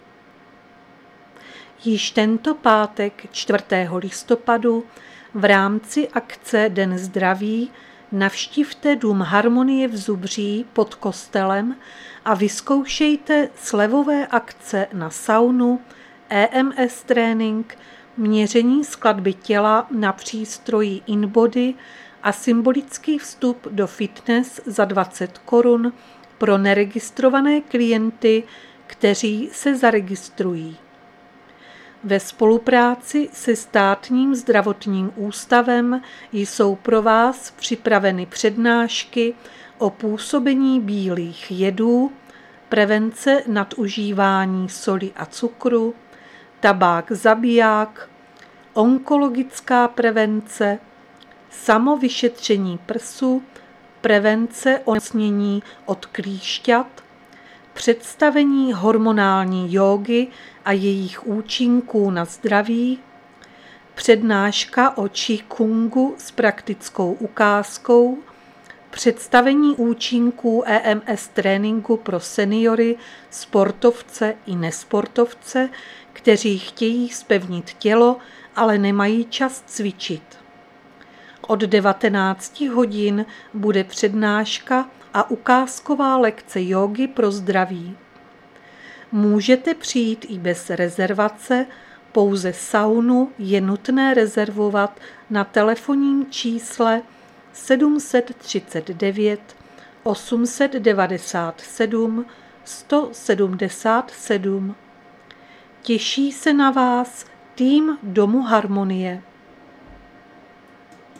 Záznam hlášení místního rozhlasu 1.11.2022